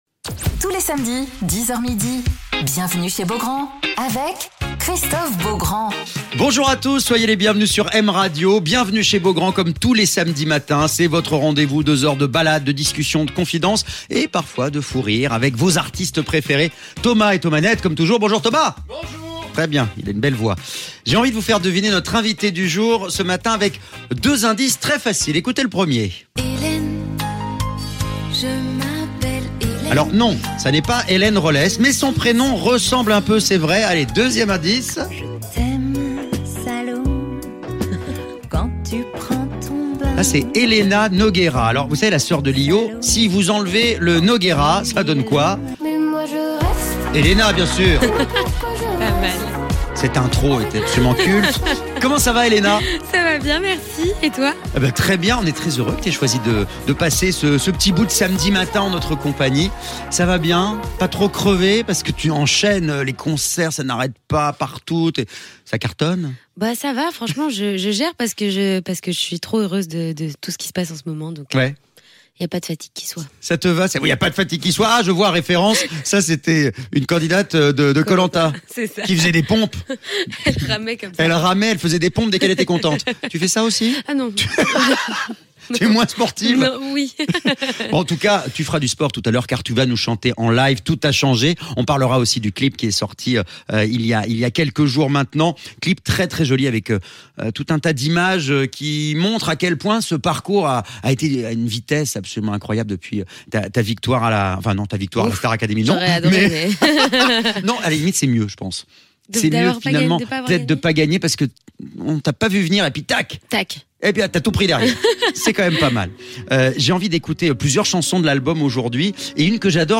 Alors qu'elle vient de sortir la réédition de son album et qu'elle vient de démarrer sa tournée, Héléna est l'invitée de Christophe Beaugrand sur M Radio